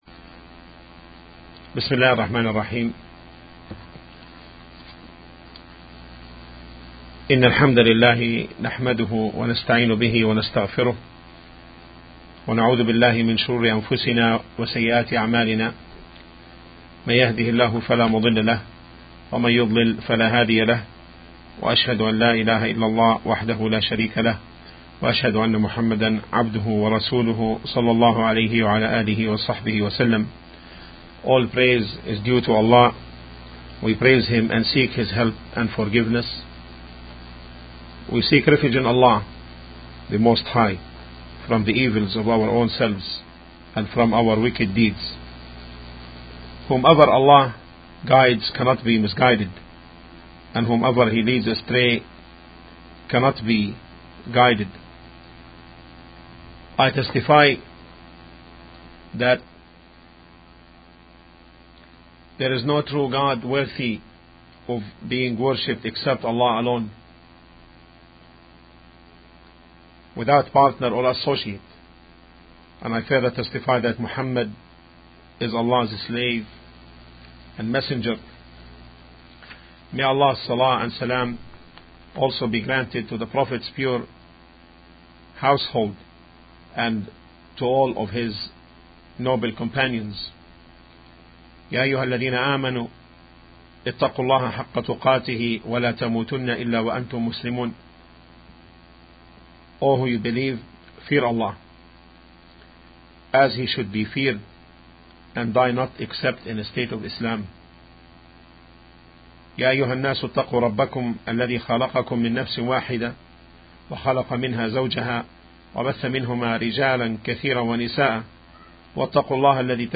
(We are very sorry to say that this Lecture is not full due to the unavailability of the records.
01-Intro-to-Islam-Introduction-To-The-Three-Fundamentals-Principles.mp3